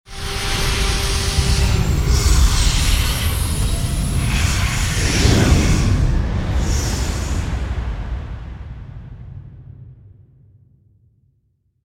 CosmicRageSounds / wav / ships / movement / launch10.wav
launch10.wav